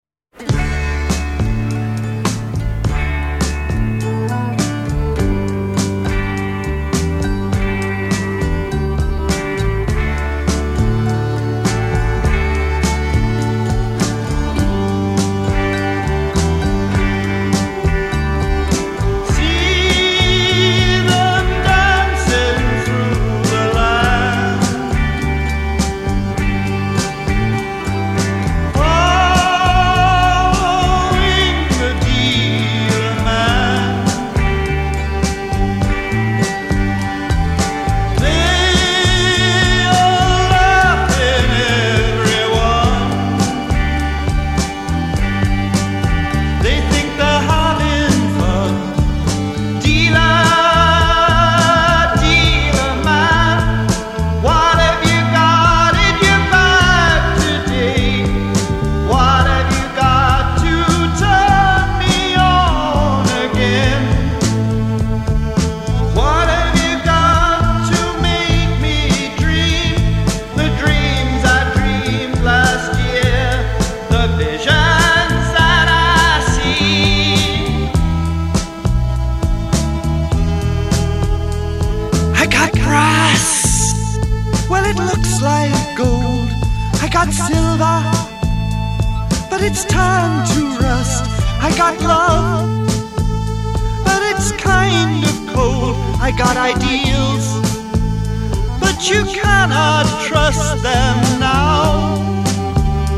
re-mastered from the original tapes